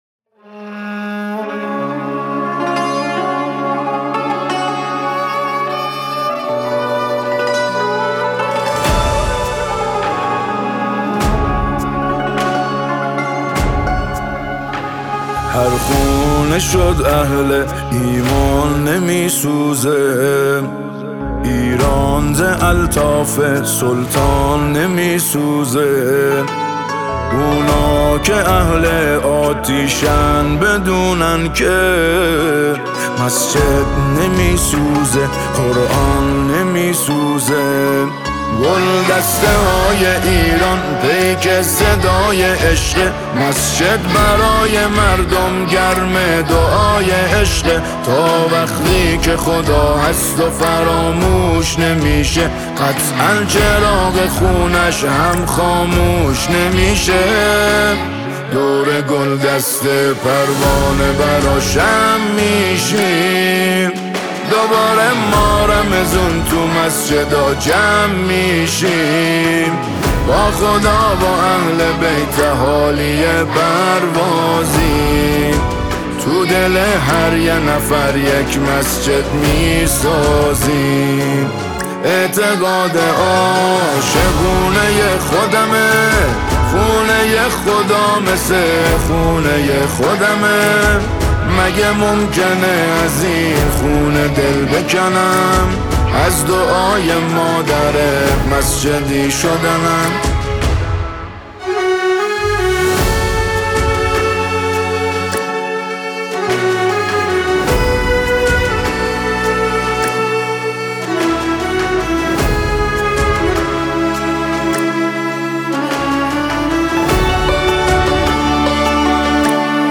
اثری دل‌انگیز و معنوی
جلوه‌ای از پیوند ایمان، شعر و موسیقی معنوی است.